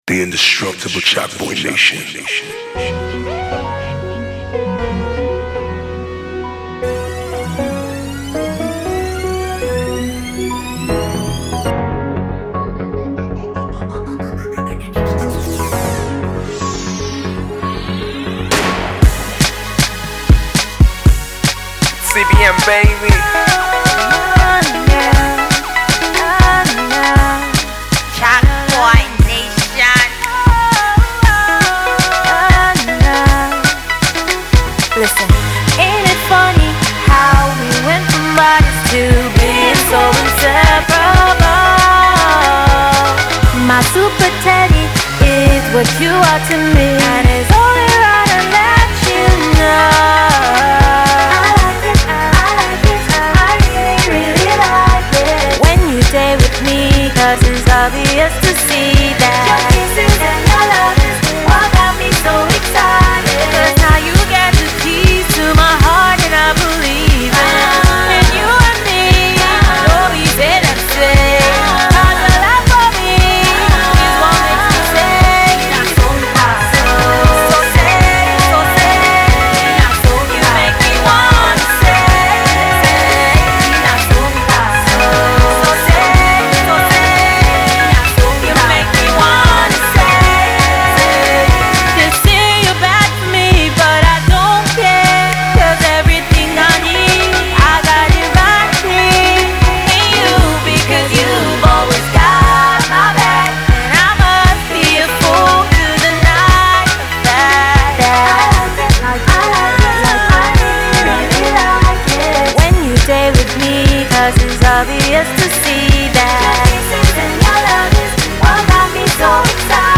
funky Afro Pop debut